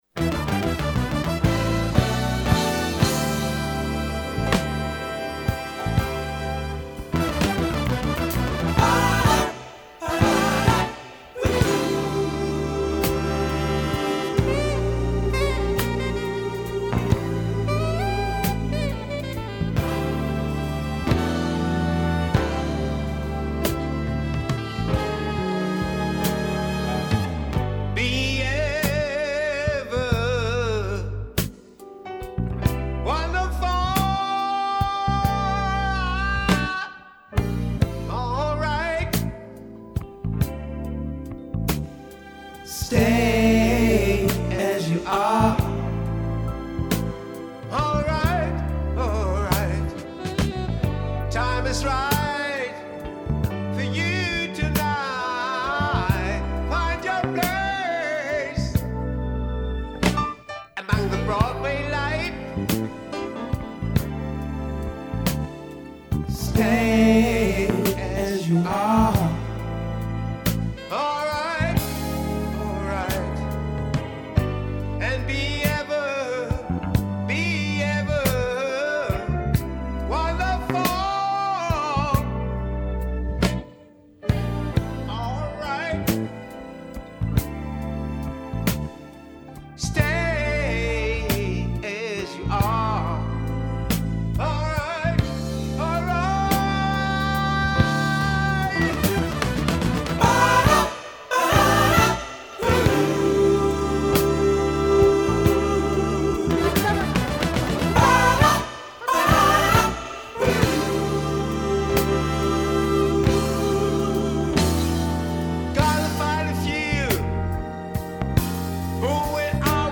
Style: RnB